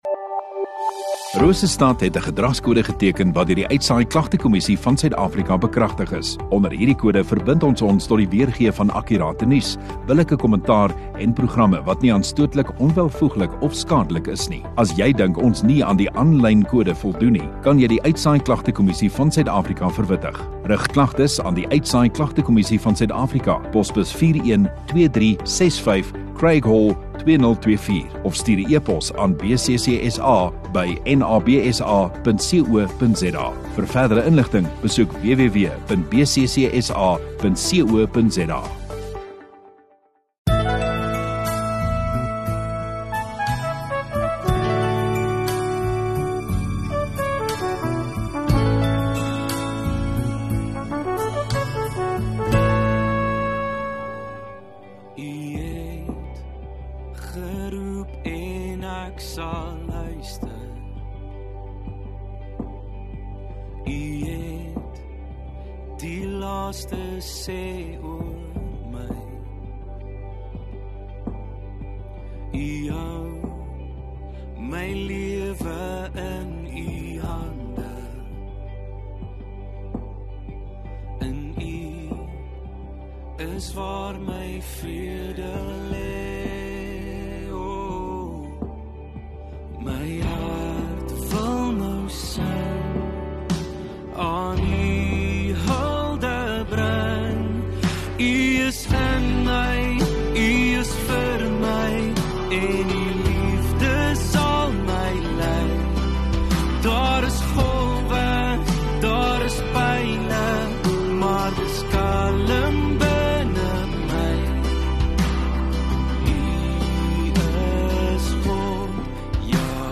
17 Dec Dinsdag Oggenddiens